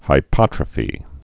(hī-pŏtrə-fē)